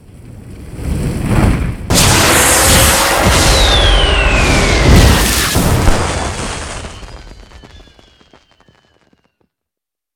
dragon.ogg